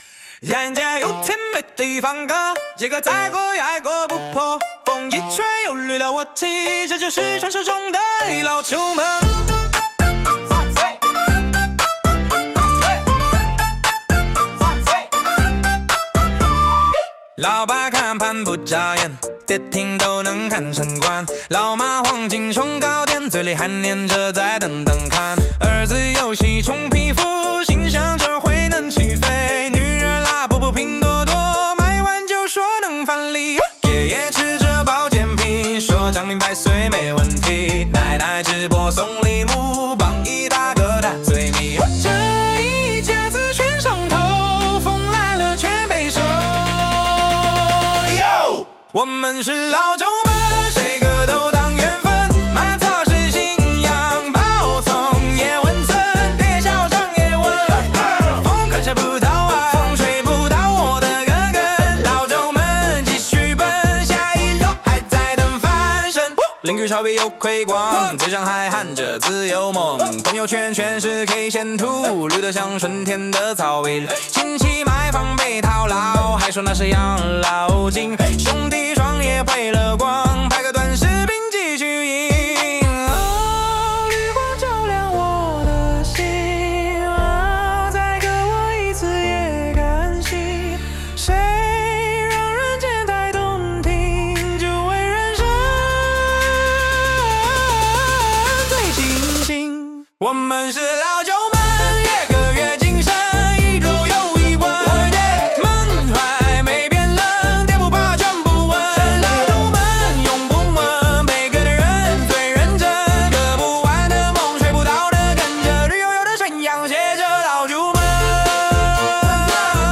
AI原创音乐